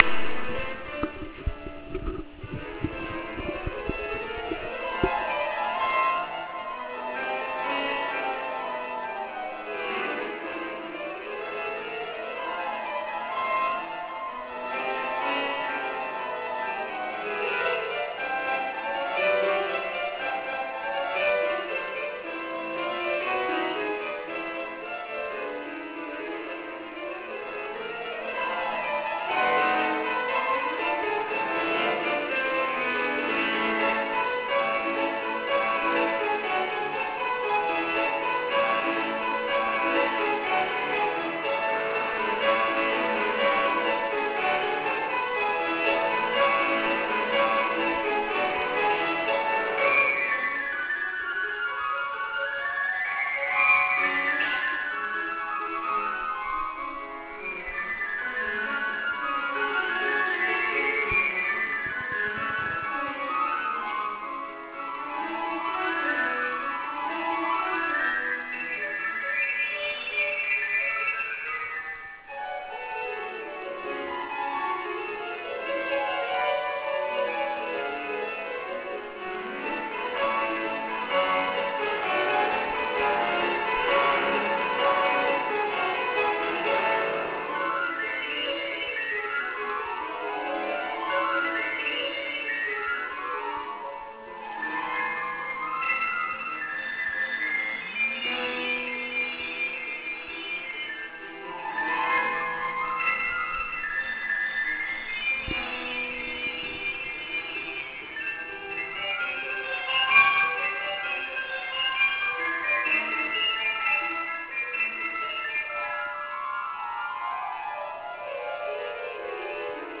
피아노